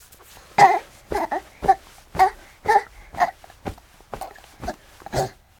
bounce.wav